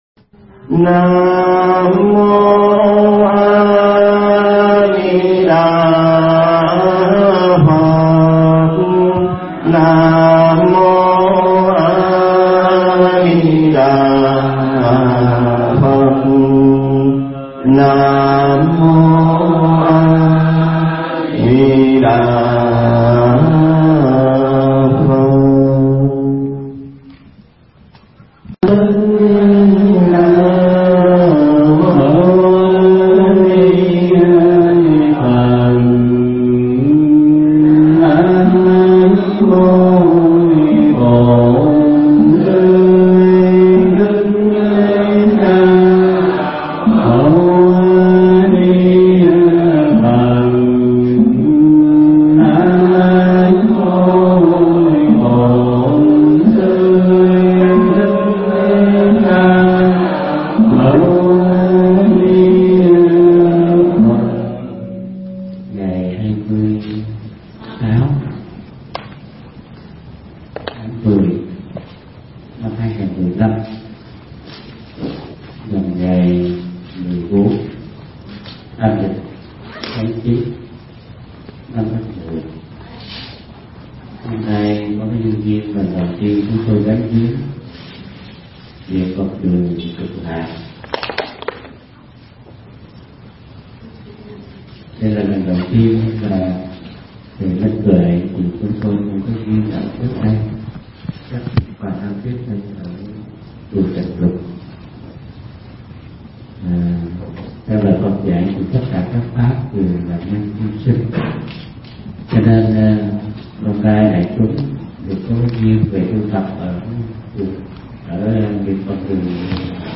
Nghe Mp3 thuyết pháp Pháp Thoại Giảng Tại Niệm Phật Đường Cực Lạc 1